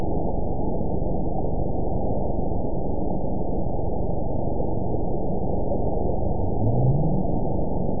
event 922864 date 04/29/25 time 02:55:29 GMT (2 days, 15 hours ago) score 9.48 location TSS-AB02 detected by nrw target species NRW annotations +NRW Spectrogram: Frequency (kHz) vs. Time (s) audio not available .wav